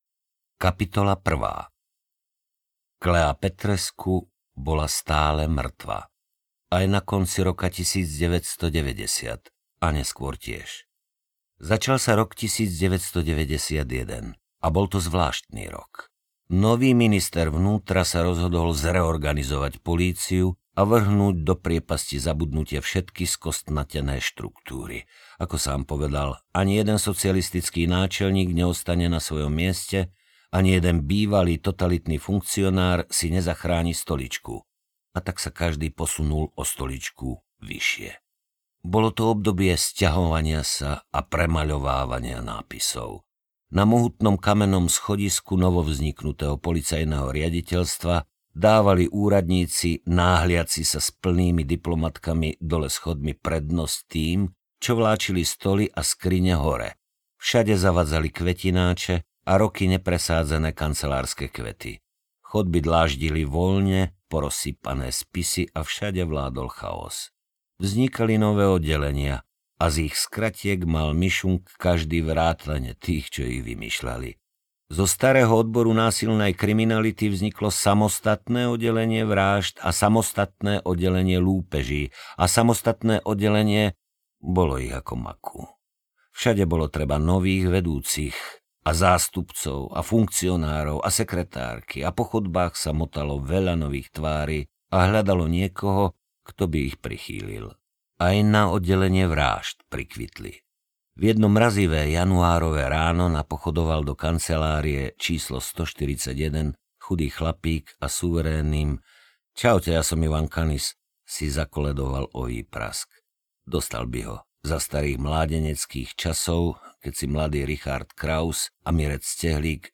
Mucholapka audiokniha
Ukázka z knihy
• InterpretMarián Geišberg